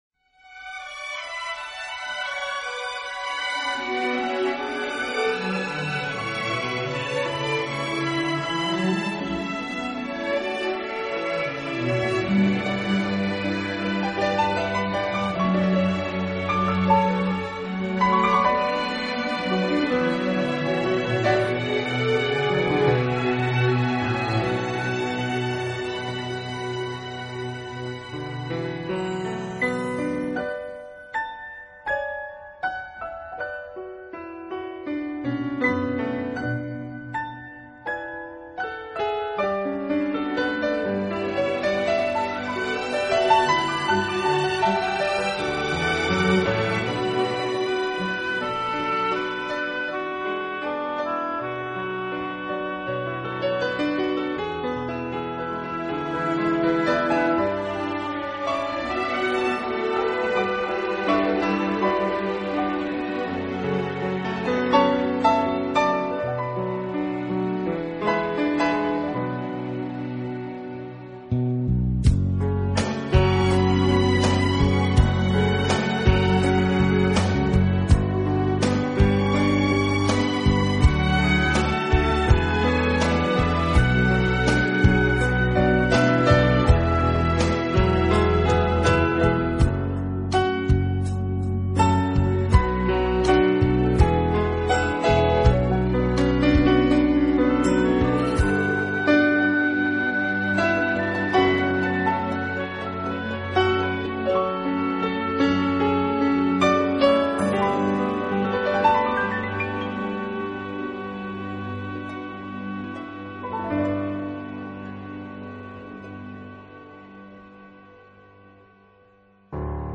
【名品钢琴】
21世纪全新华语演奏专辑